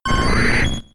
Cri de Roucoups K.O. dans Pokémon X et Y.